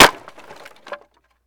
wood_plank_break2.wav